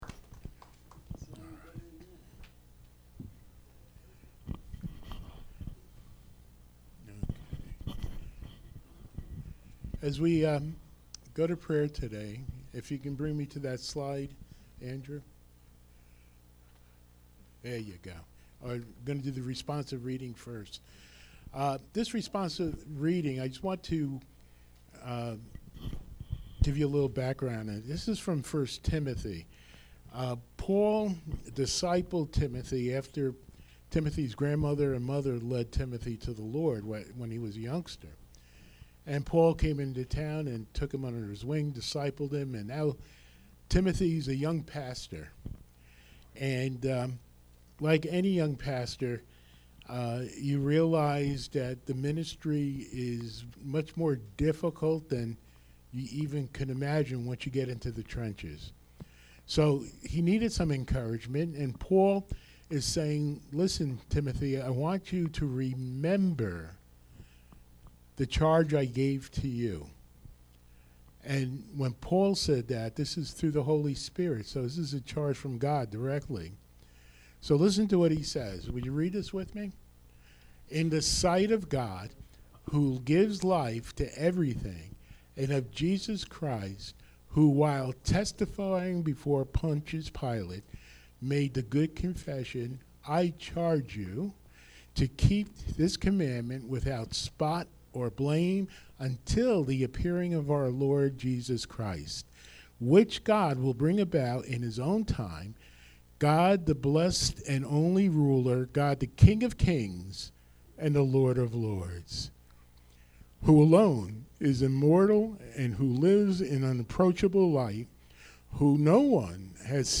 Podcast (sermons)